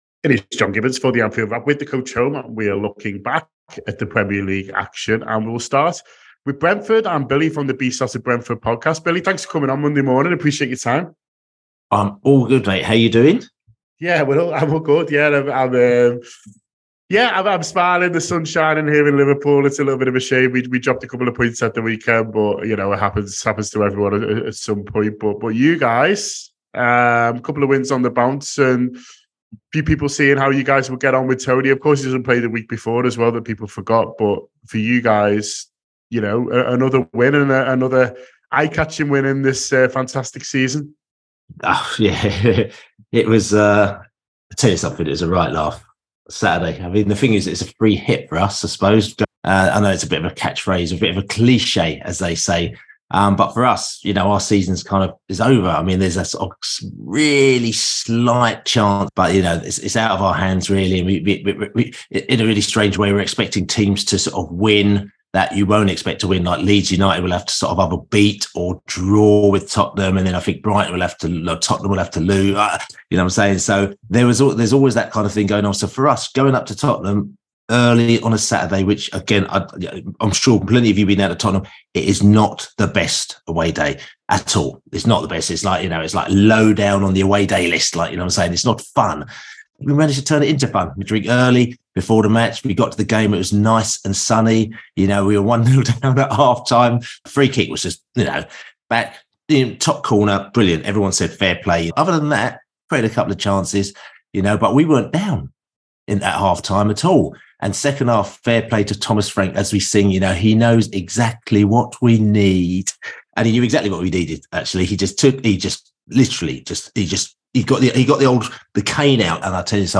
Below is a clip from the show – subscribe for more on the other Premier League teams…